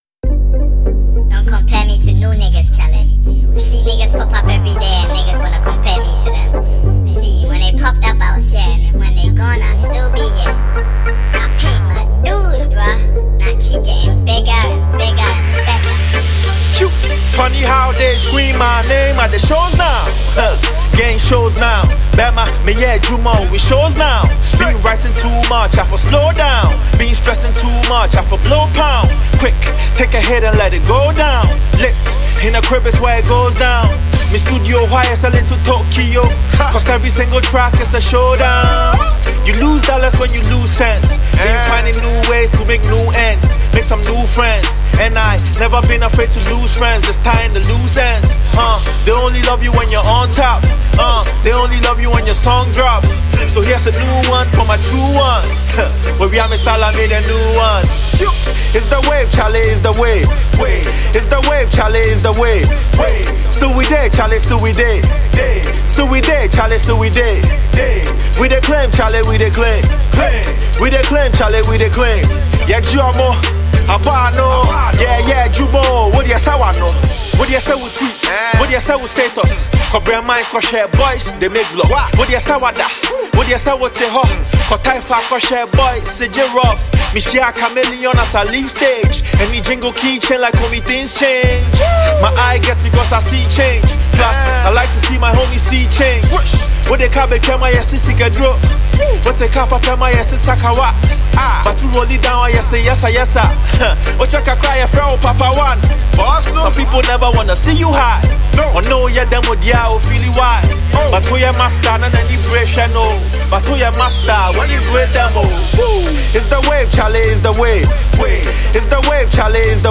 Hip-Hop song